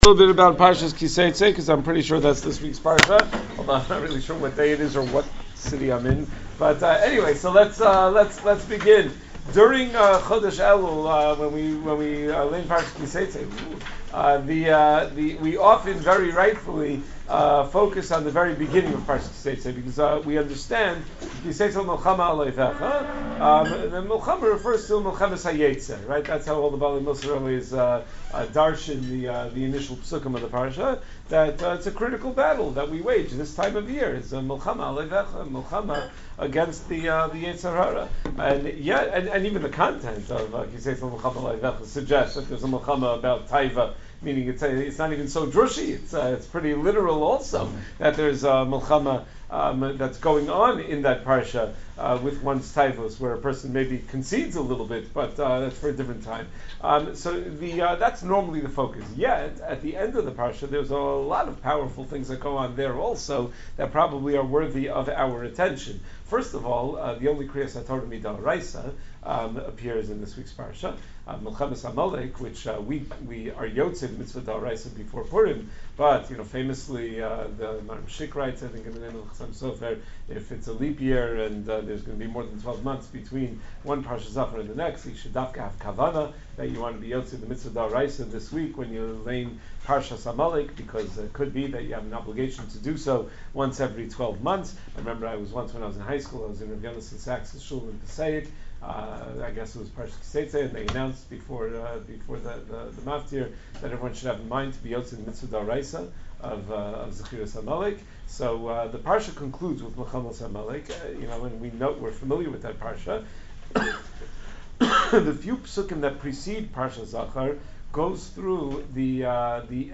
Ki Seitzei Derasha - Three Motivations for Sin